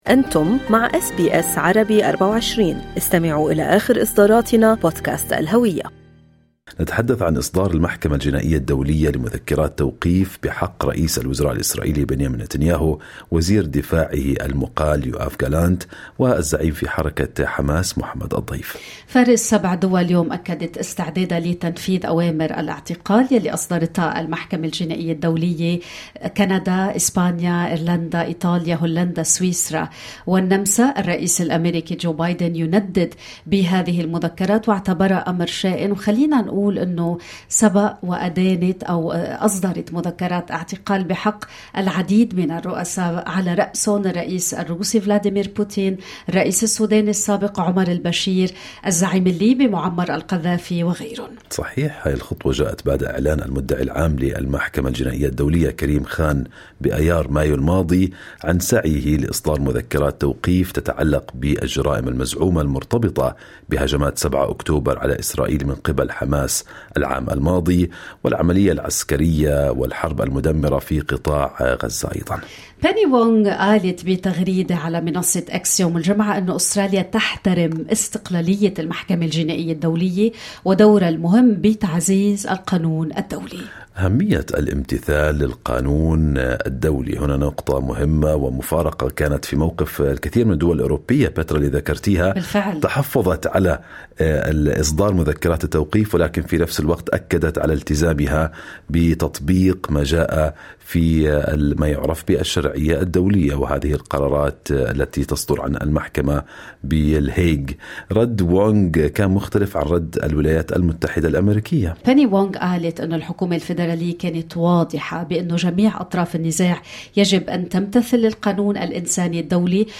نحلل موقف أستراليا بالمقارنة مع موقف الإدارة الأمريكية إزاء إصدار مذكرات التوقيف بحق نتنياهو وغالانت والضيف مع الكاتب والمحلل السياسي في واشنطن